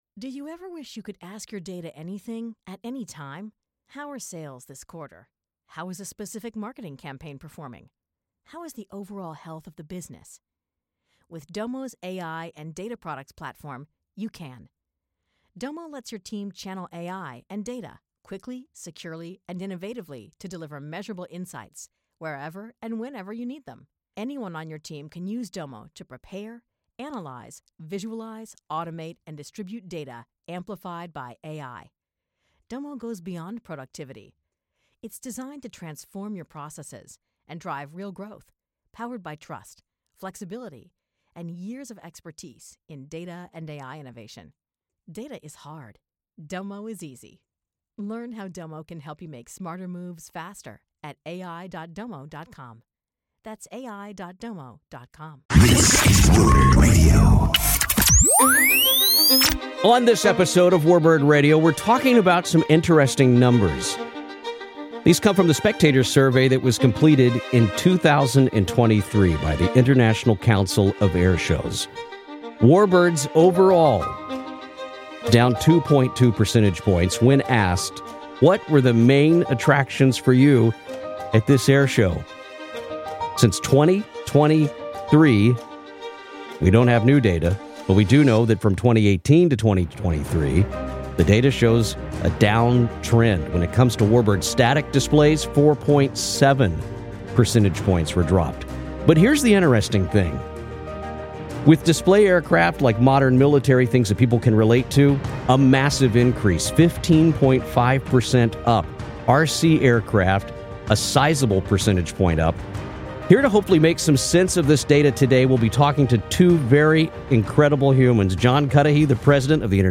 The conversation will focus on findings from the 2023 Spectator Survey conducted by ICAS, which indicates that static warbird displays have seen a 4.7% decline in spectator interest since 2018, and when you factor in the 2000-2016 average, they've fallen 7.2% overall.